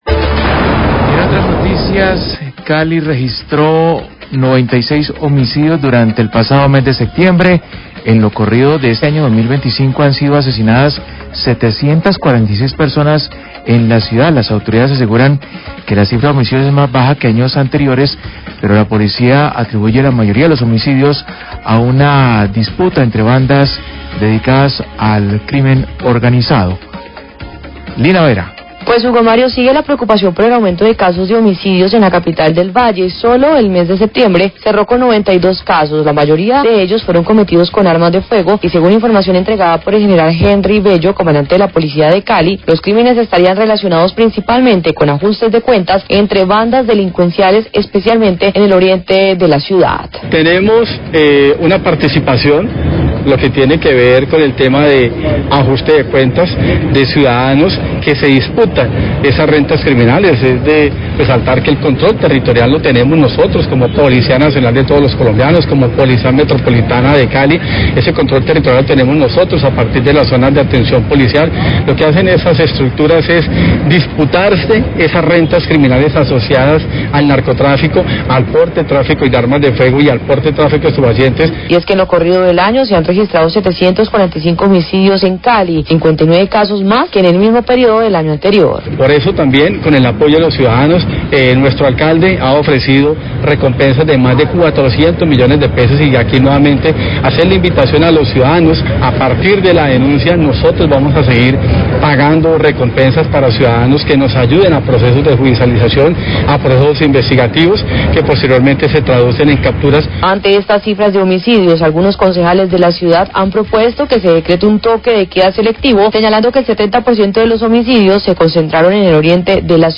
Radio
Habla comandante de la Policía Cali, Henry Bello.